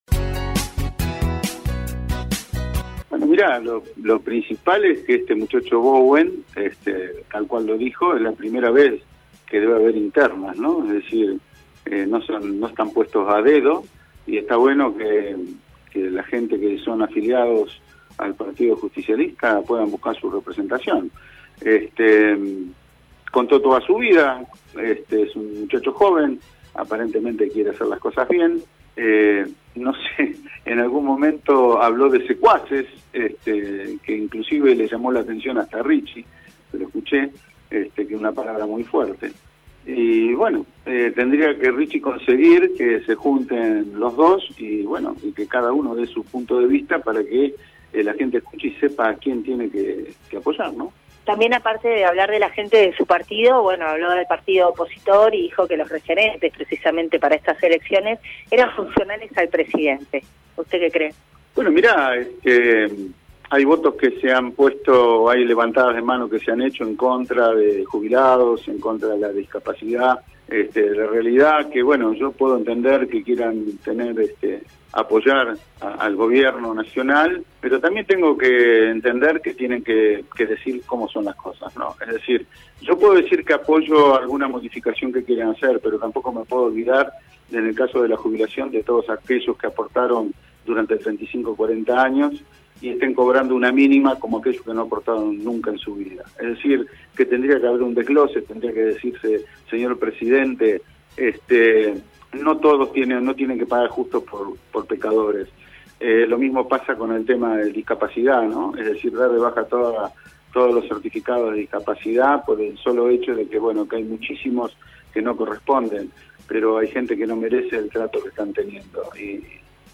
El concejal del Plich Omar Lattanzio, siempre dispuesto a brindar su opinión, sobre todo cuando recibe tiros por elevación, dialogó con LA MAÑANA DE HOY